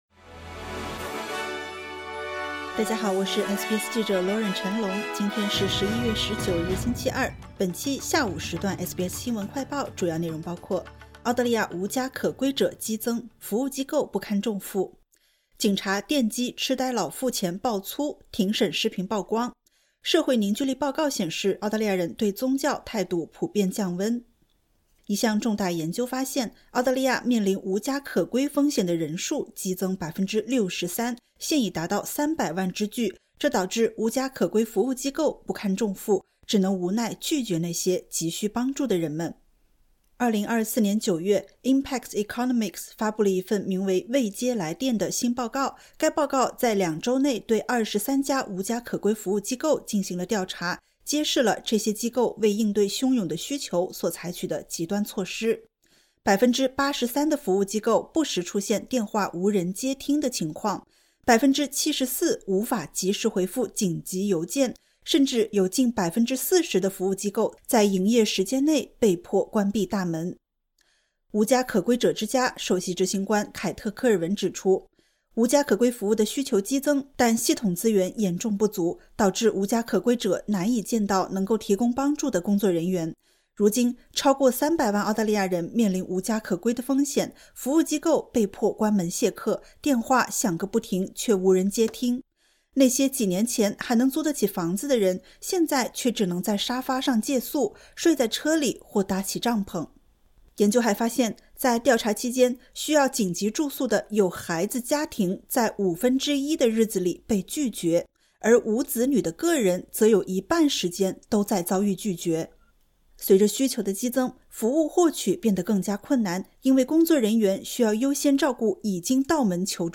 【SBS新闻快报】澳大利亚无家可归者激增 服务机构不堪重负